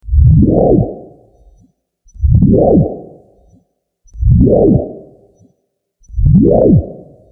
Back Cancel dow.wav